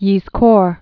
(yēz-kôr, yĭzkər)